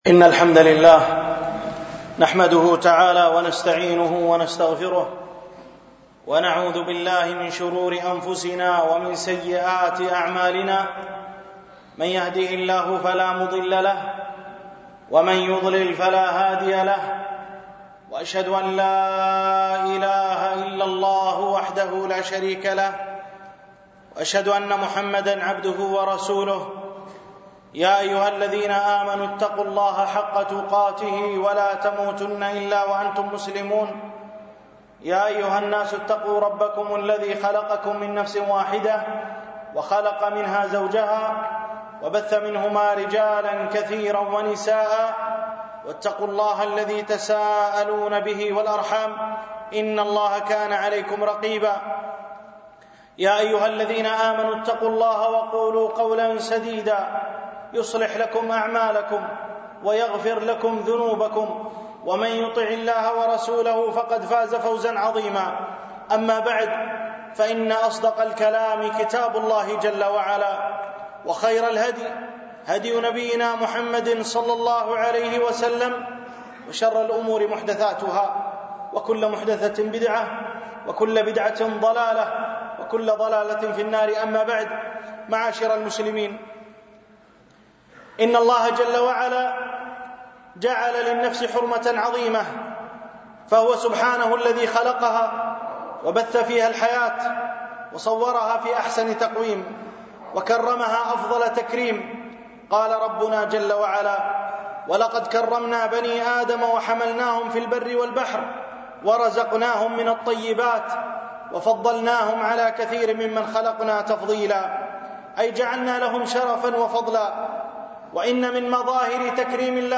حرمة دم الإنسان الألبوم: دروس مسجد عائشة